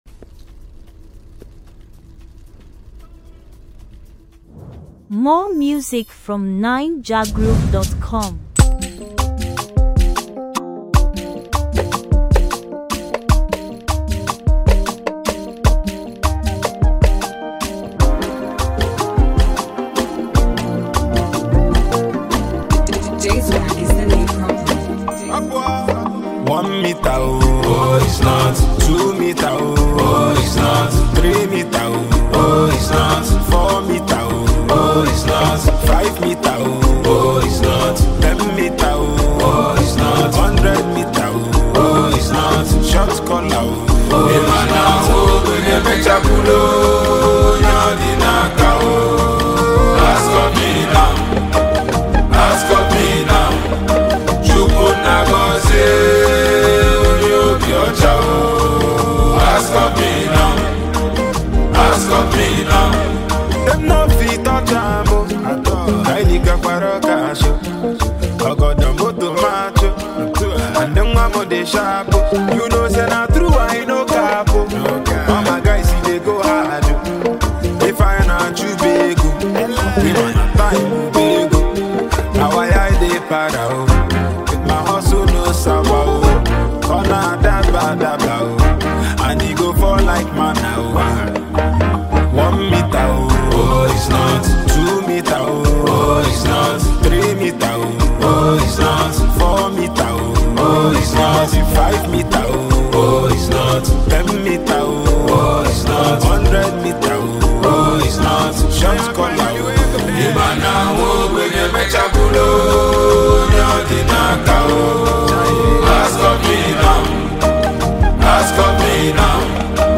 Latest, Naija-music, African-music